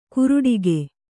♪ kuruḍige